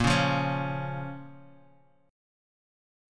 A#m chord